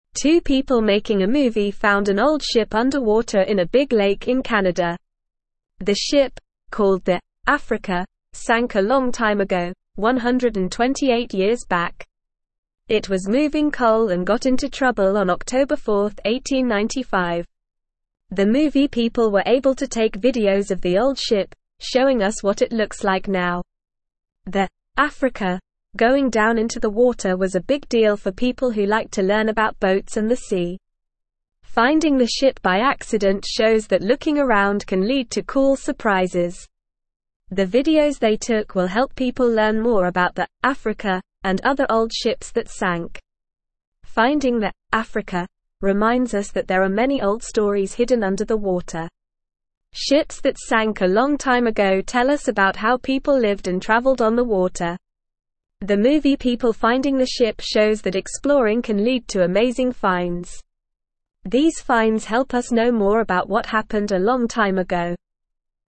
Normal
English-Newsroom-Lower-Intermediate-NORMAL-Reading-Surprise-Find-Old-Boat-Underwater-Helps-Us-Learn.mp3